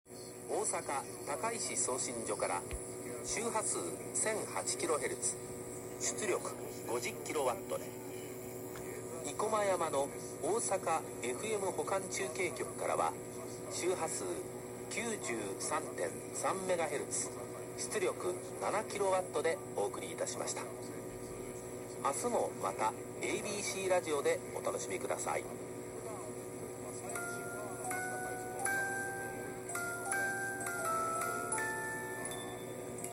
ここでアンテナ端子にアンテナをつなぎました。聞こえて来たのは、ABC放送。
「7MHzを受信してるのに何で93.3MHz?? ダイヤル回してもきこえるのはABCだけ」
これがその時の受信音です。